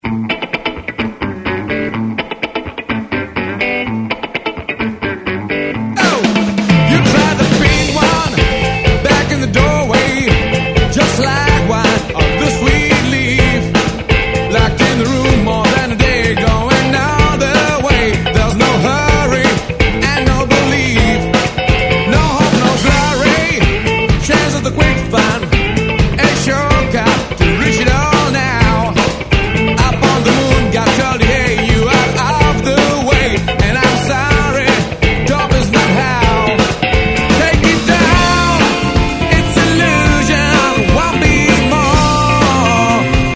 Odpíchnutý rock s prvky funky, blues a řadou dalších vlivů